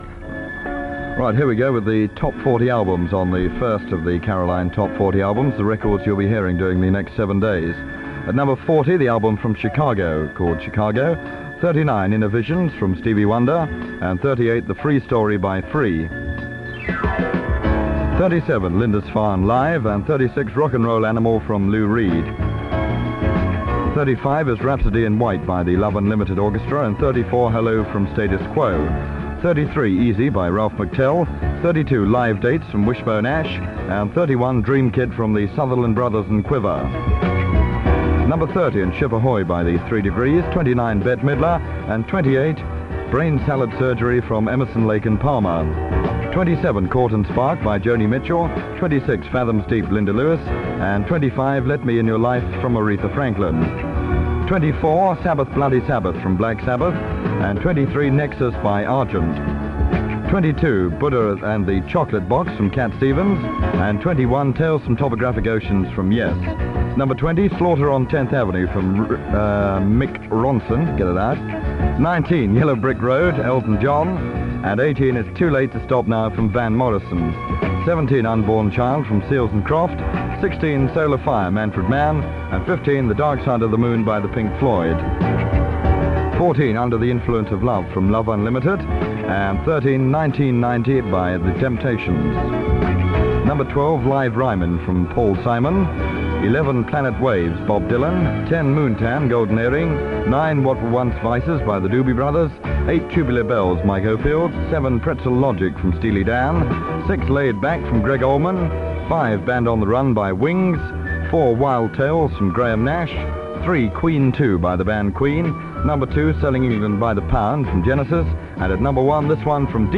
It was broadcast on 26th March 1974.